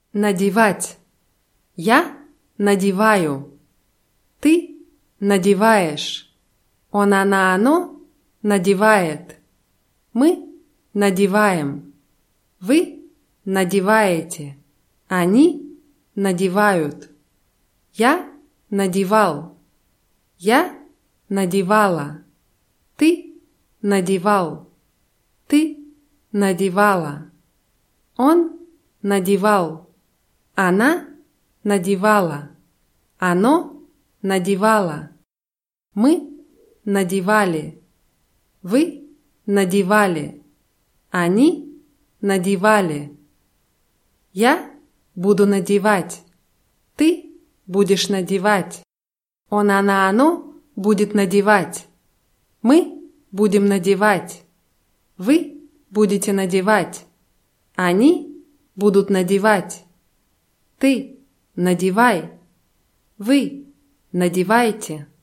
надевать [nadʲiwátʲ]